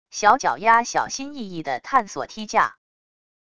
小脚丫小心翼翼地探索梯架wav音频